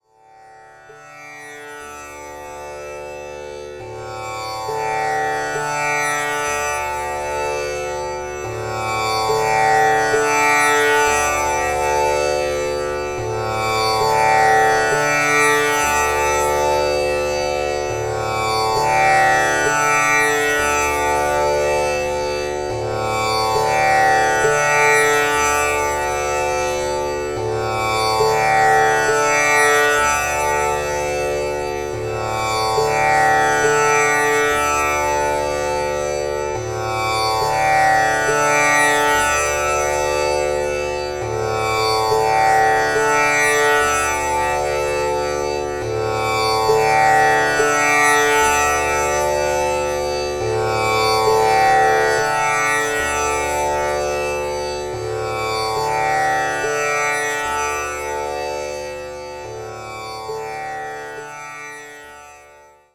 Tanpura
Bij mijn tanpuraspel kun je een diepe innerlijke rust gaan ervaren.
Bij het bespelen van de 4 snaren op de tanpura in een gelijkmatig ritme vervloeien de 4 tonen in elkaar en krijg je een klank die constant doorgaat. Er ontstaan boventonen en er is geen begin en geen eind.
tanpura.mp3